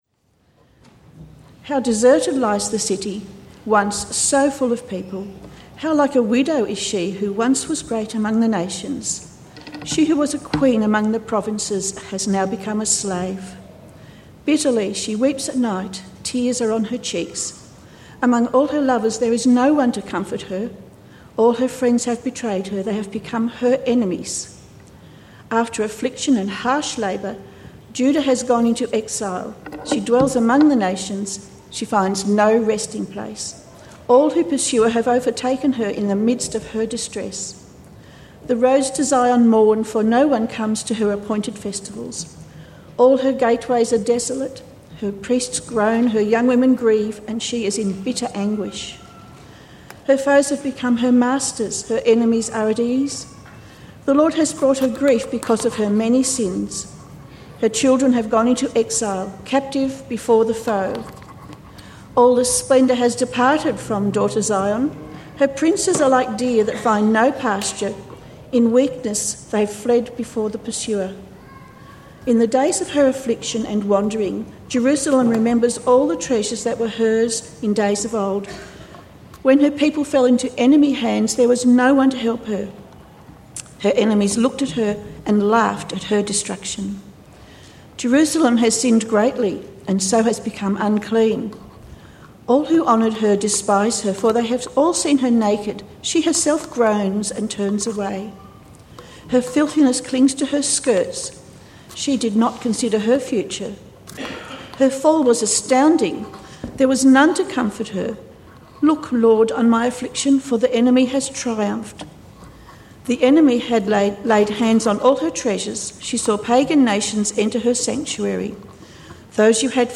Sermon – A Festal Garment (Lamentations)